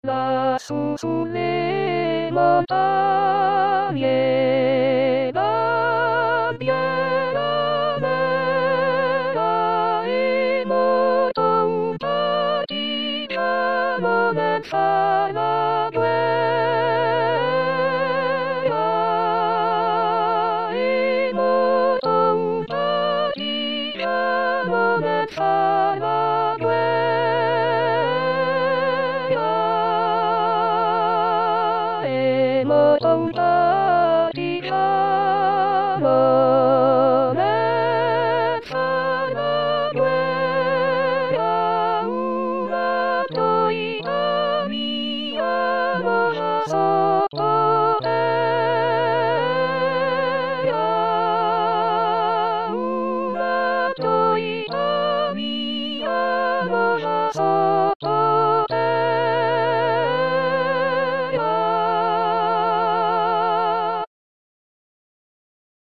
Mp3 di studio
soprani